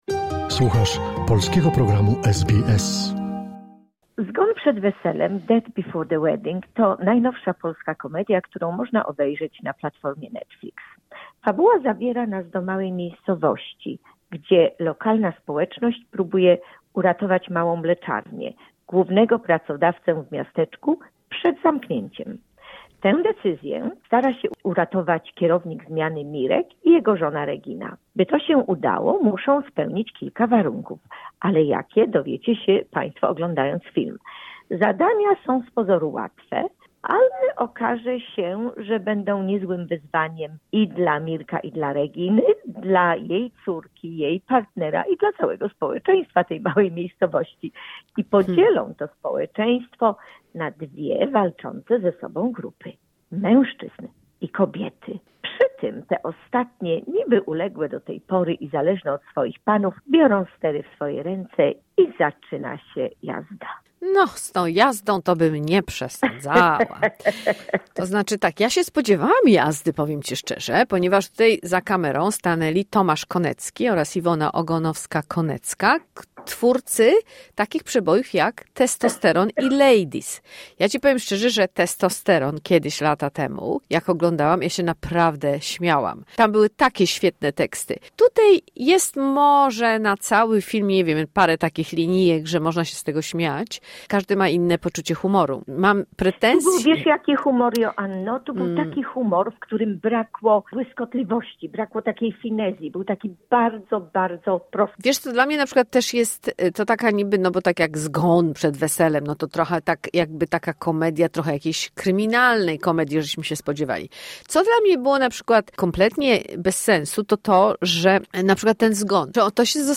"Zgon przed weselem" - recenzja filmowa